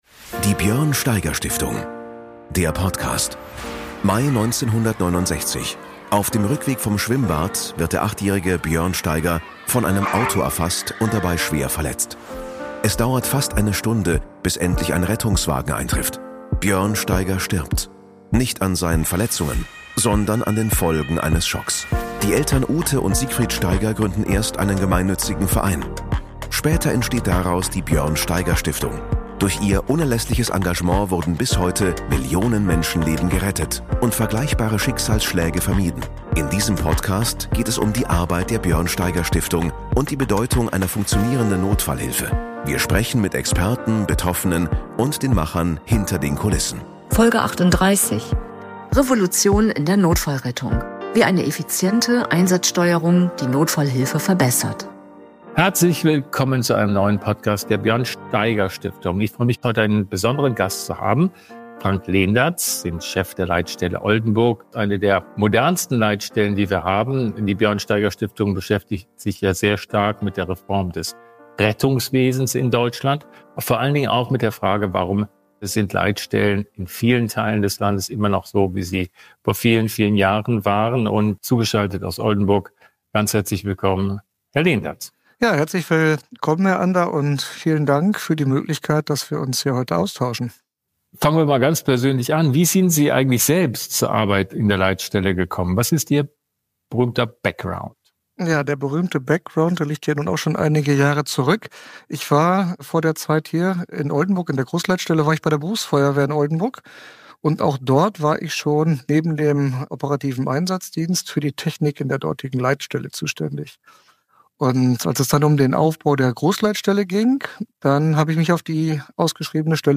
Und warum braucht es dringend ein Umdenken in der deutschen Leitstellenstruktur? Ein Gespräch über Technik, Verantwortung – und mutige Veränderung im Sinne der Patientensicherheit.